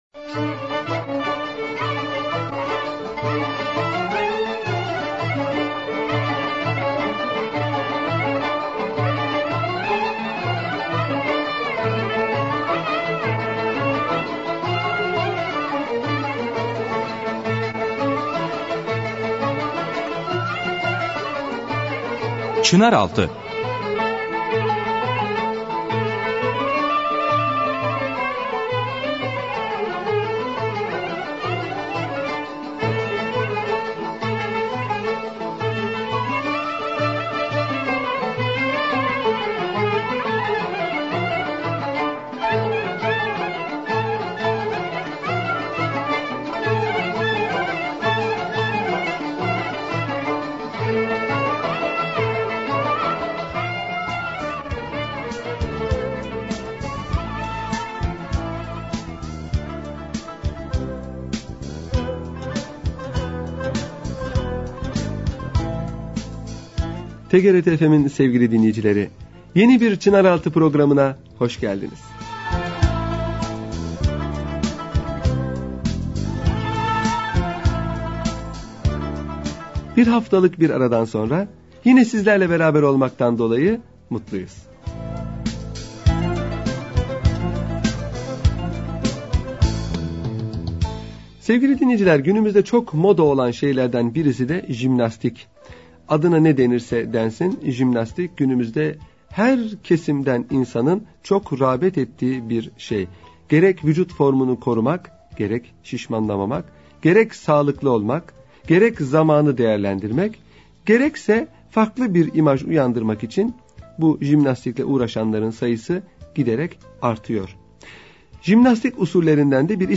Radyo Programi - Jimnastik